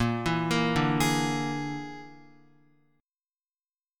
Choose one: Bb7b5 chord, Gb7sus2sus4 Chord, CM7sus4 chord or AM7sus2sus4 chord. Bb7b5 chord